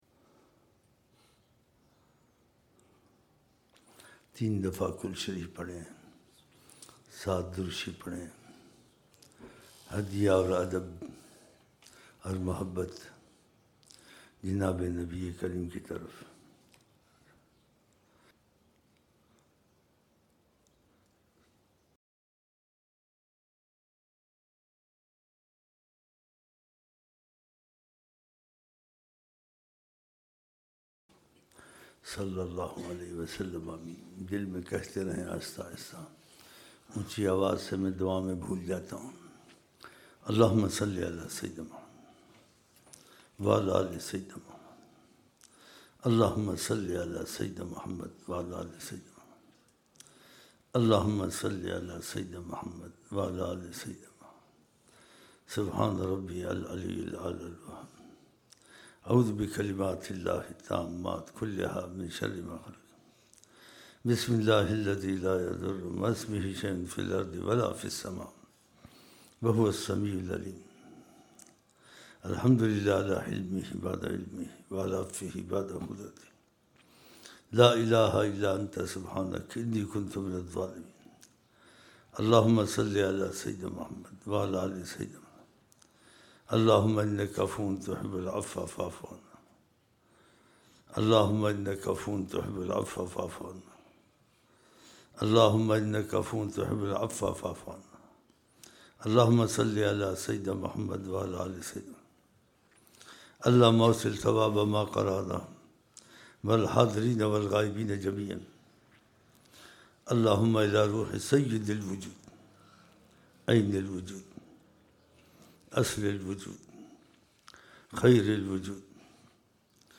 07 January 2007 Sunday Asr Mehfil (16 Dhul Hijjah 1427 AH)
Dua – 14:30 Minute